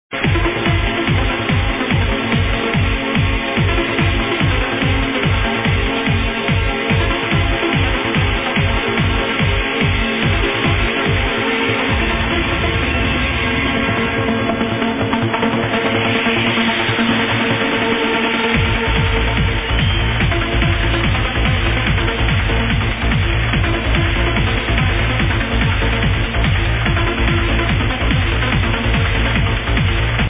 Anybody could help me to identifie this trancer?